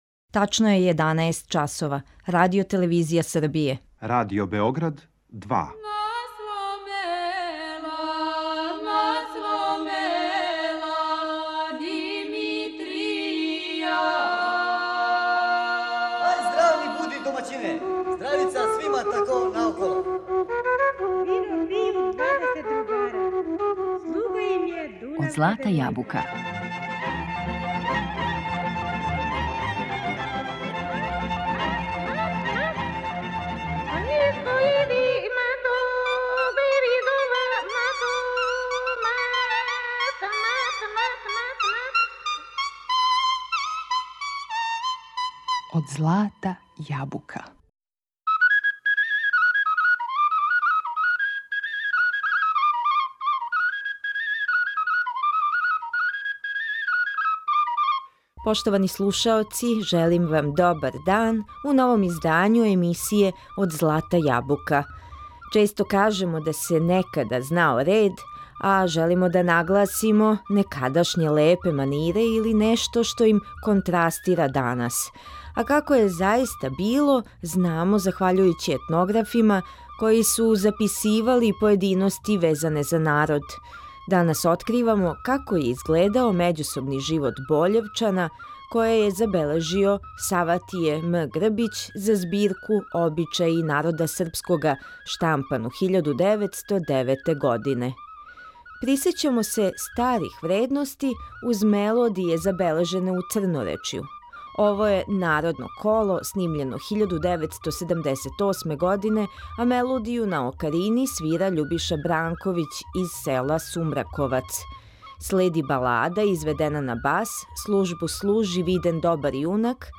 Данас откривамо како је изгледао међусобни живот Бољевчана, које је забележио Саватије М. Грбић за збирку "Обичаји народа Српскога", штампану 1909. године. Музички репертоар данешњег издања емисије Од злата јабука чине песме са чувеног бољевачког фестивала "Црноречје у песми и игри".